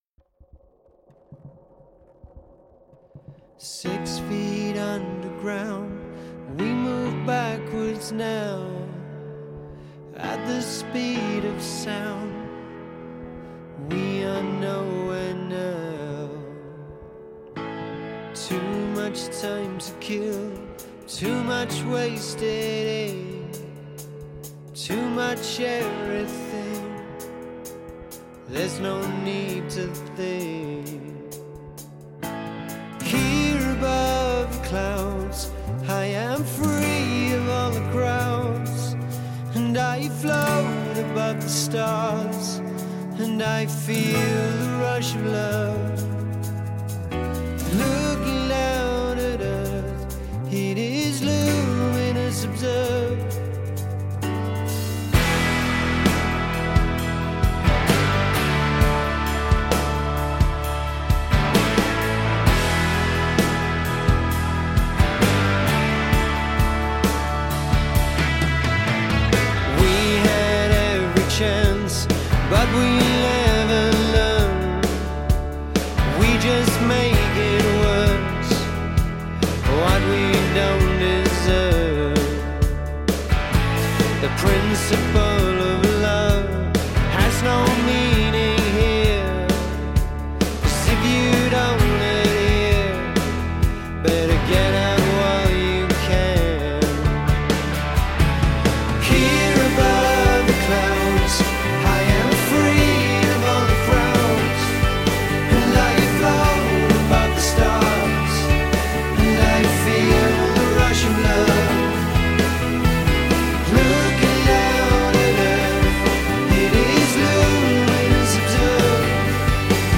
He wanted to go pop-rock.